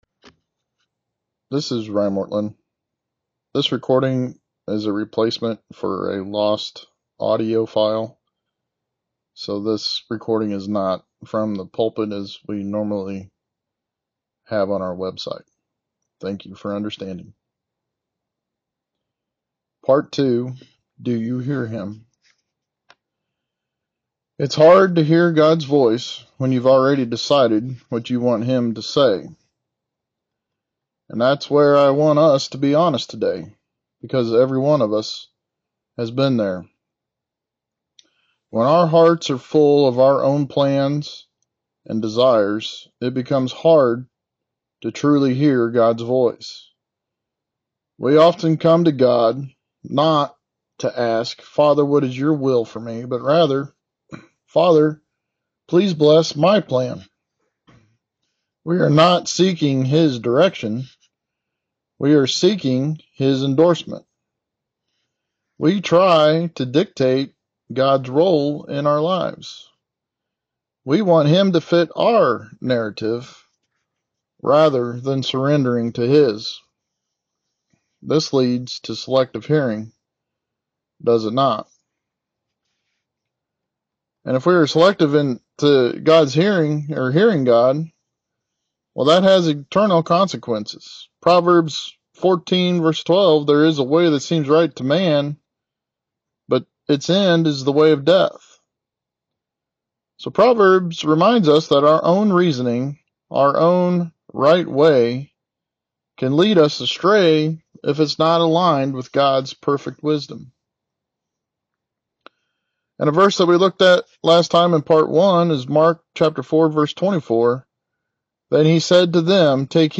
Lesson Recording